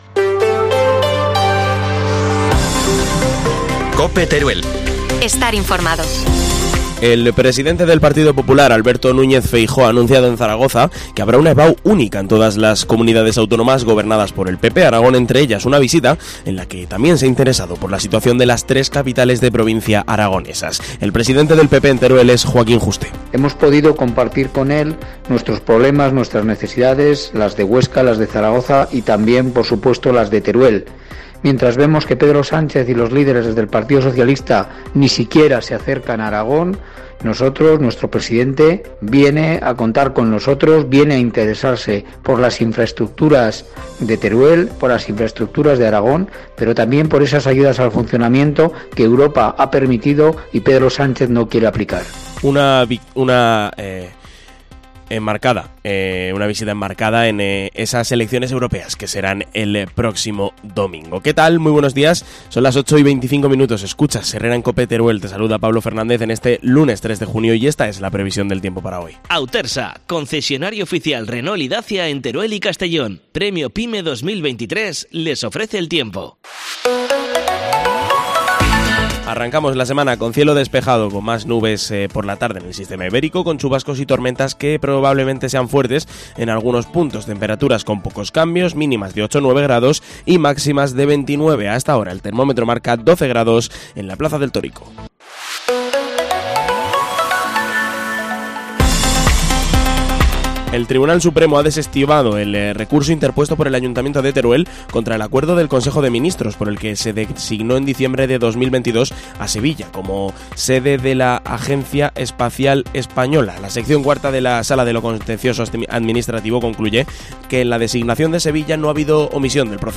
AUDIO: Titulares del día en COPE Teruel